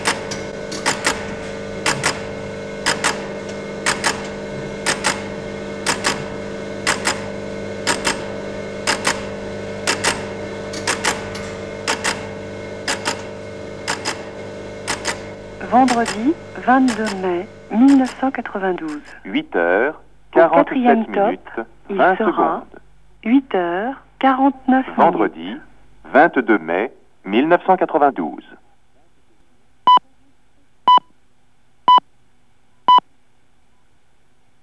Accessible en composant un numéro dédié, elle énonce l’heure avec une précision à la seconde près, rythmée par un signal sonore.